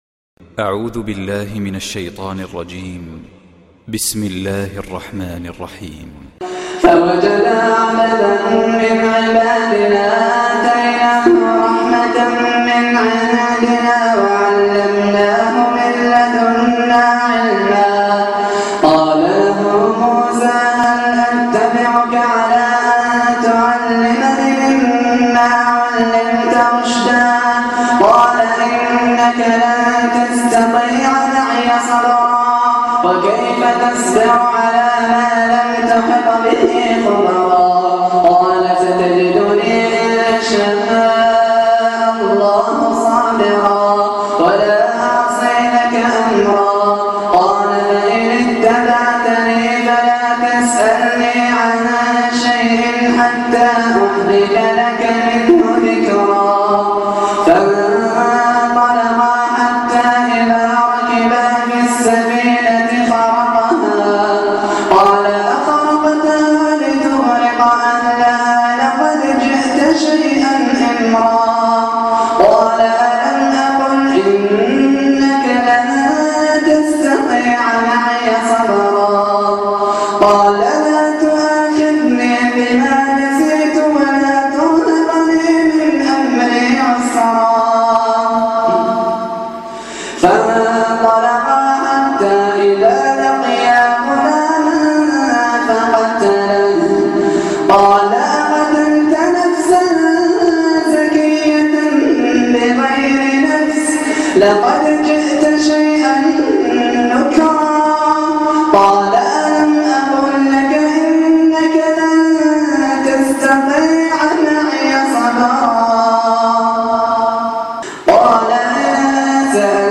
القرآن الكريم مقطع رائع للقارئ الجزائري